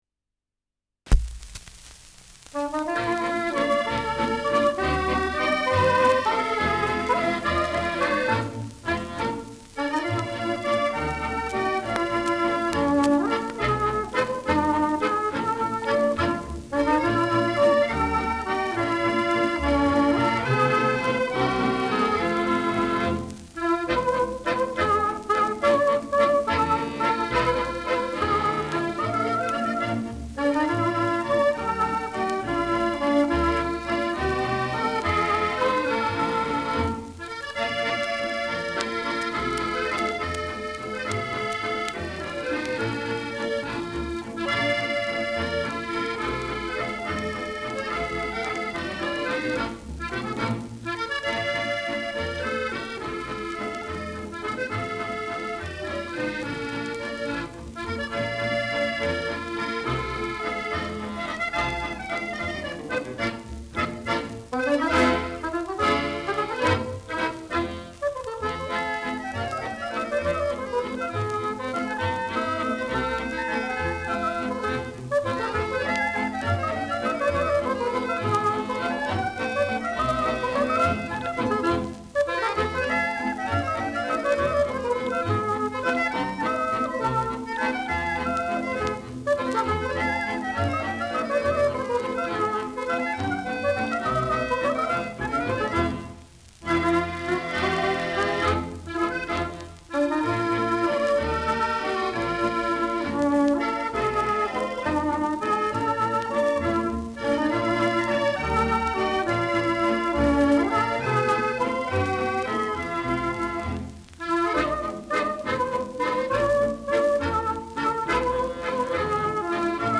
Schellackplatte
Ländlerkapelle* FVS-00018